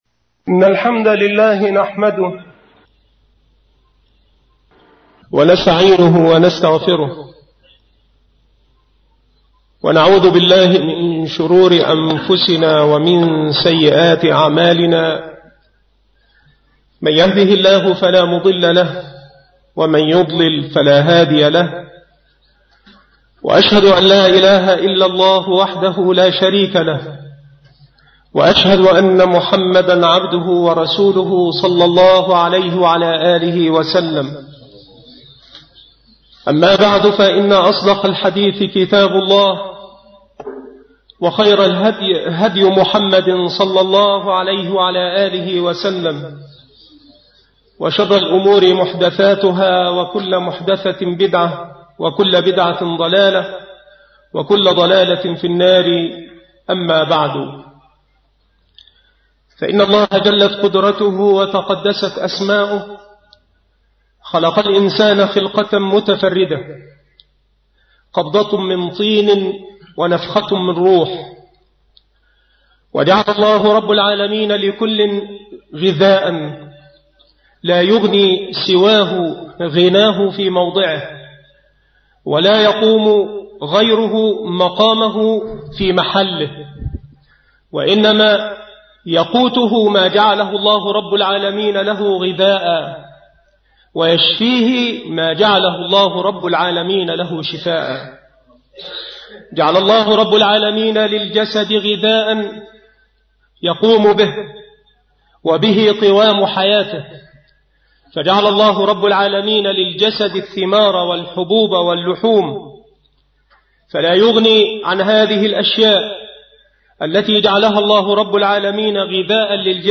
مكان إلقاء هذه المحاضرة بالمسجد الشرقي - سبك الأحد - أشمون - محافظة المنوفية